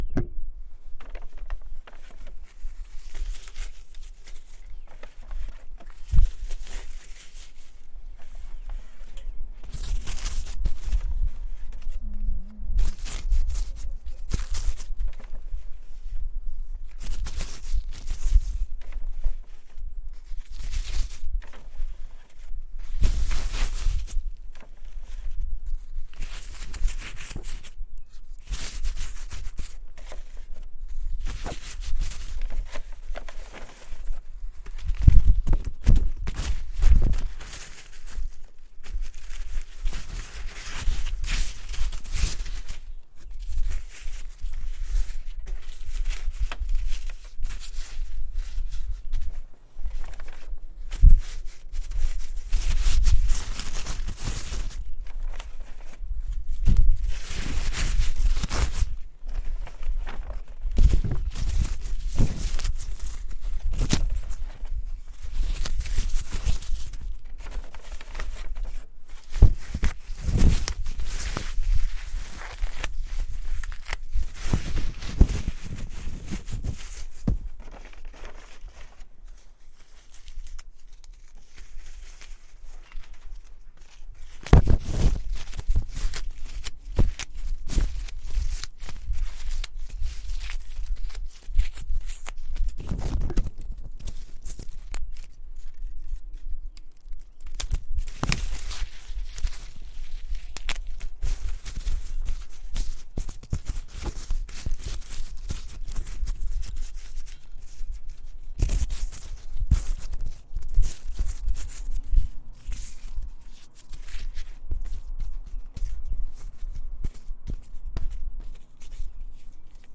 recorded outdoors with a Zoom H4n Handy Recorder